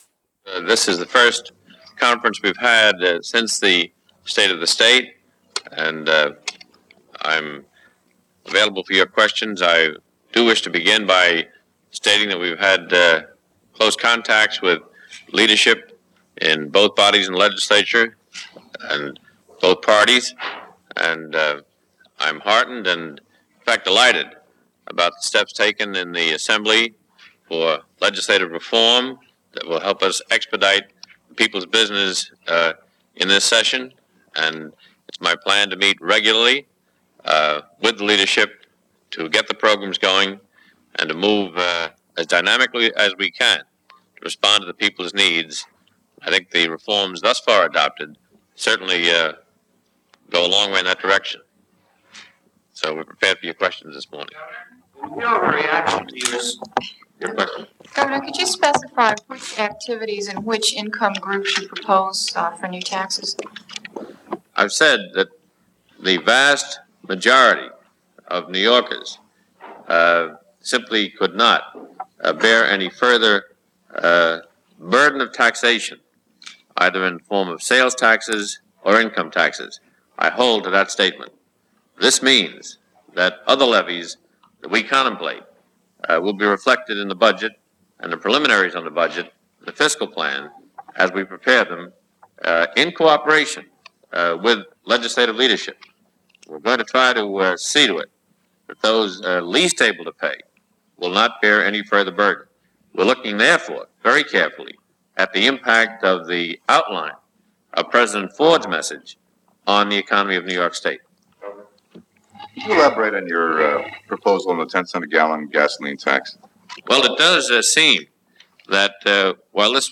New York Governor Hugh Carey, giving his first Press conference after delivering his State Of The State address a week earlier and his first Press conference as Governor, having been elected in November of 1974.
Hugh-Carey-Presser-1975.mp3